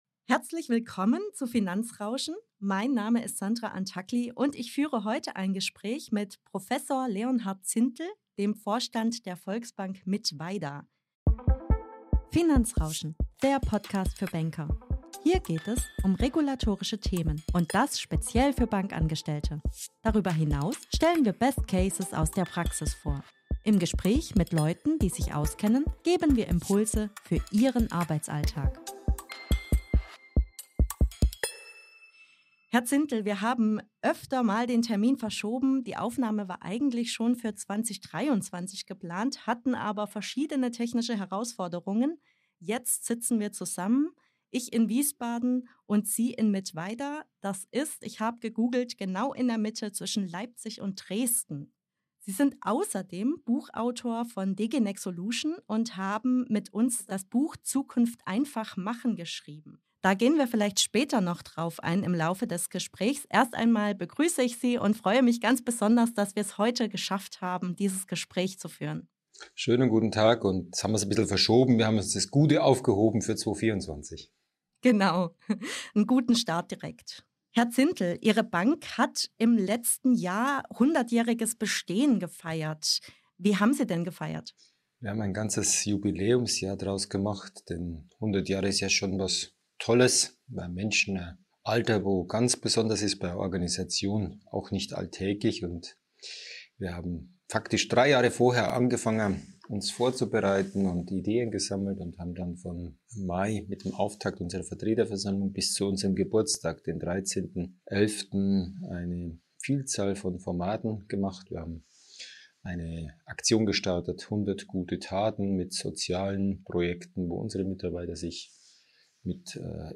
Ein exklusives Interview